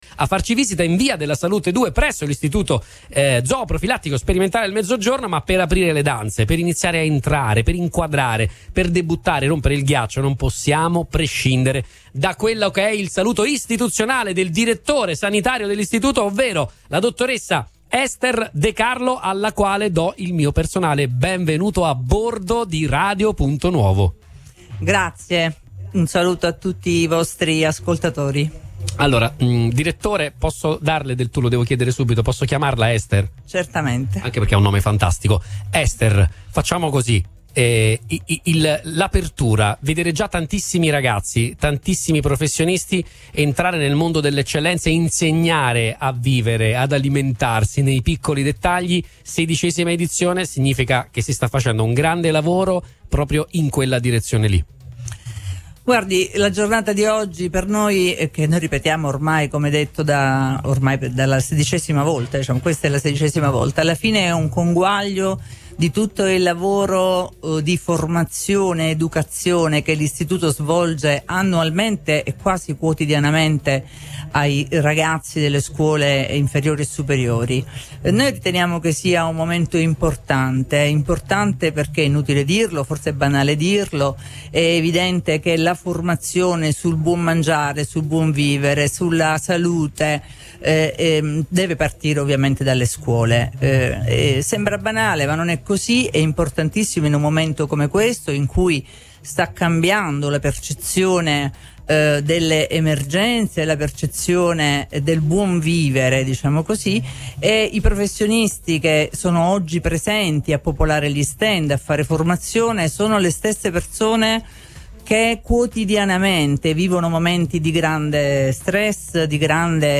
Fattorie Aperte, la sedicesima edizione è un grande successo
DI SEGUITO IL PODCAST DELLE INTERVISTE